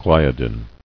[gli·a·din]